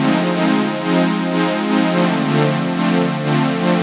cch_synth_loop_nice_125_Fm.wav